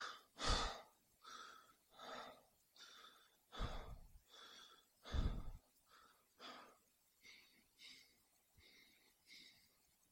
沉重的呼吸03
Tag: 呼气 吸气 呼气 呼吸 呼吸 弗利